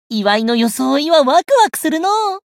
觉醒语音 祝福的装束让人心情激动 祝いの装いはわくわくするのう 媒体文件:missionchara_voice_584.mp3